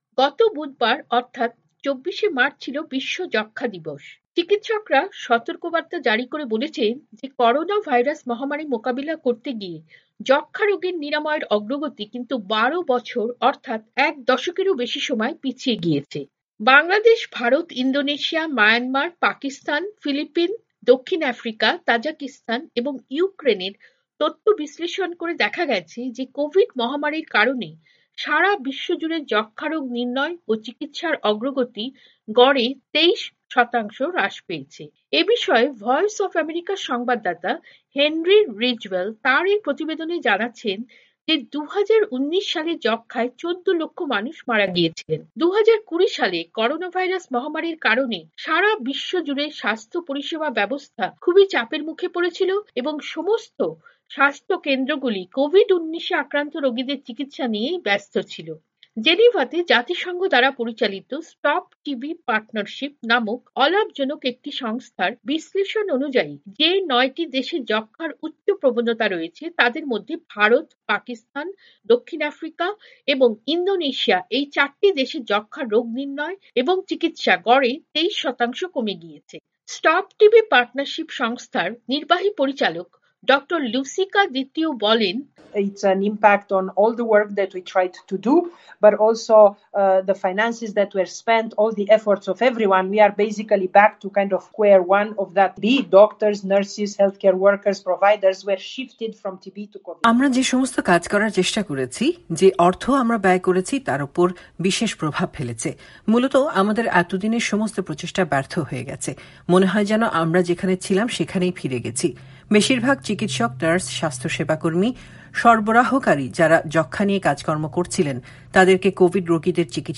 প্রতিবেদন পড়ে শোনাচ্ছেন